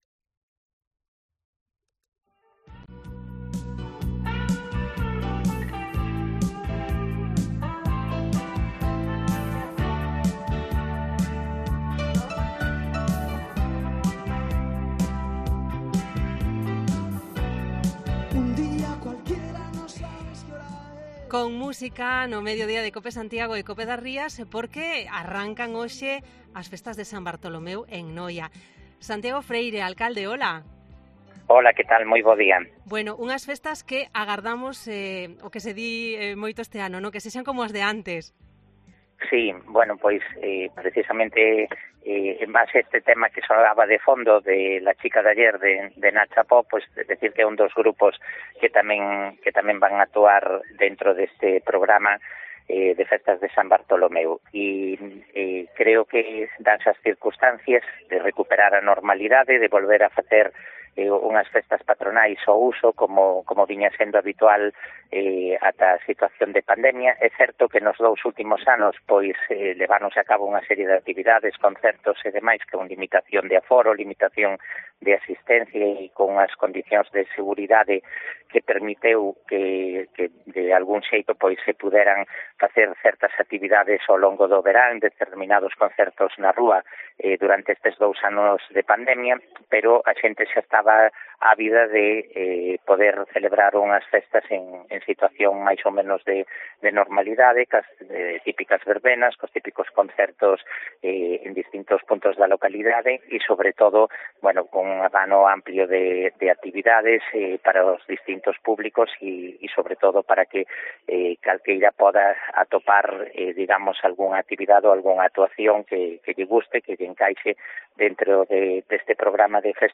Entrevista con Santiago Freire, alcalde de Noia